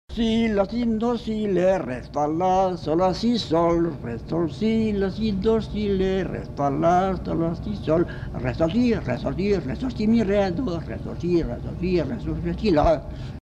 Polka piquée (notes chantées)